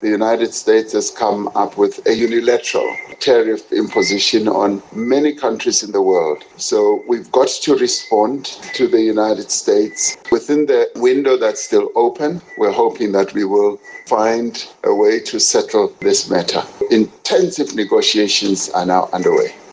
President Cyril Ramaphosa has confirmed that intensive negotiations are underway with the United States, as South Africa seeks tariff exemptions on key exports such as vehicles, steel, aluminium, and citrus. Speaking at the Union Buildings, Ramaphosa said Pretoria has presented a comprehensive trade package aimed at mutual investment and economic cooperation.